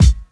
• '90s Smooth Hip-Hop Kick Sound C Key 467.wav
Royality free bass drum tuned to the C note. Loudest frequency: 549Hz